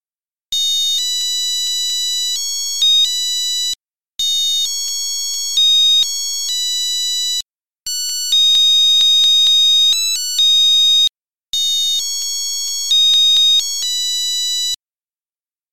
einem fröhlichen Seemannslied